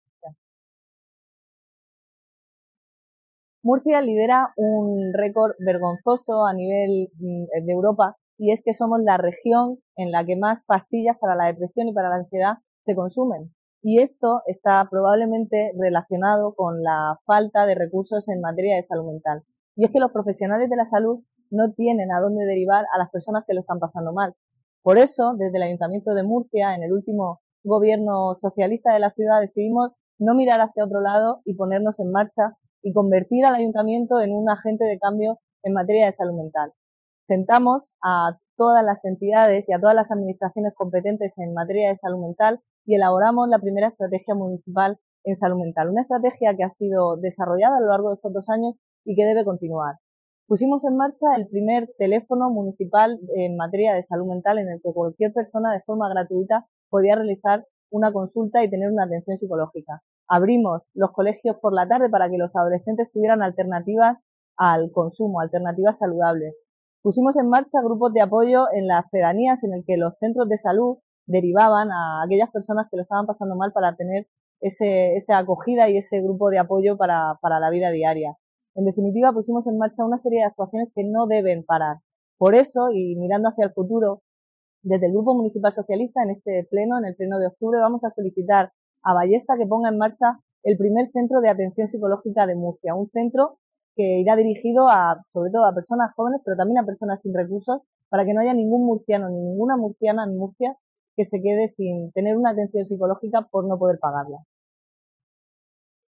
Esther Nevado, concejala del PSOE en el Ayuntamiento de Murcia